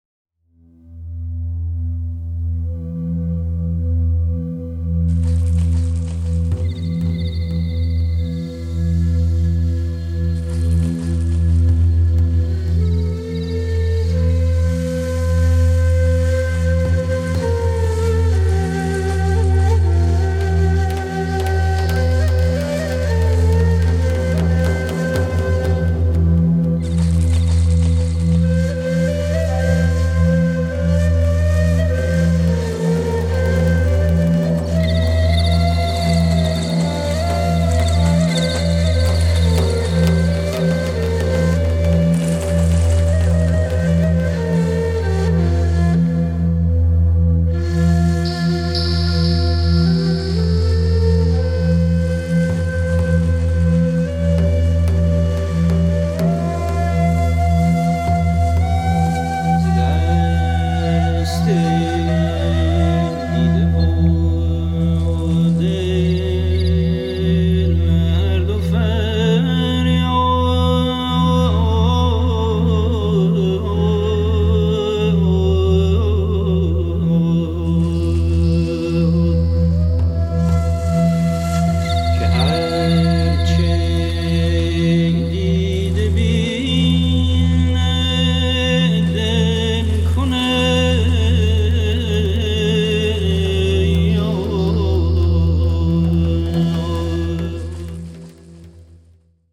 Genre: World Fusion.